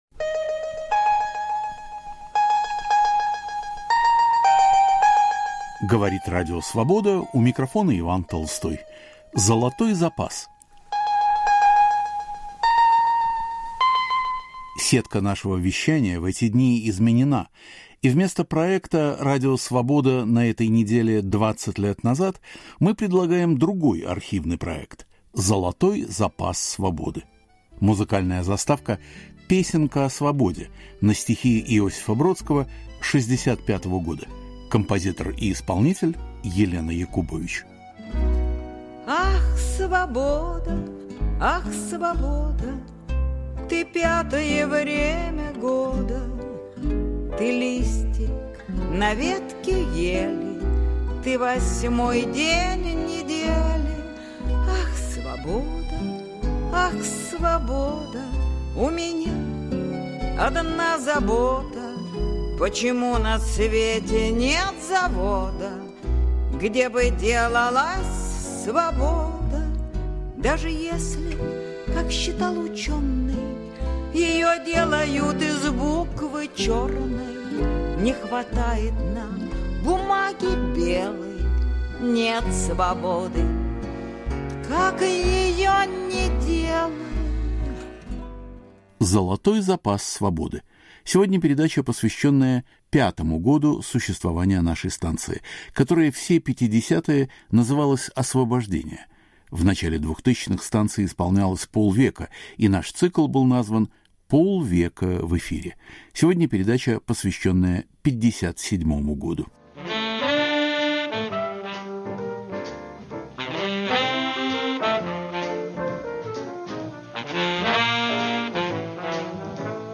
Исторический цикл к 50-летию Радио Свобода.